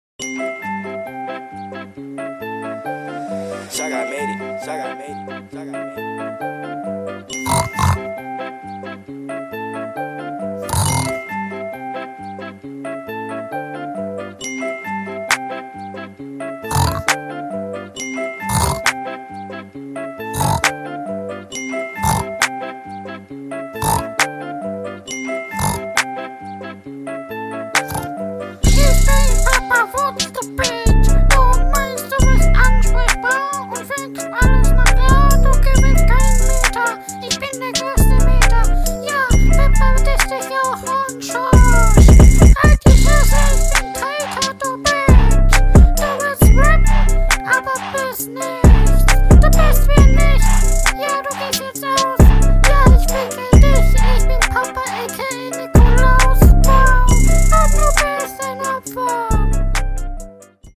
Der Beat war ganz gut und ein Text war Hammer gut und dein Takt war …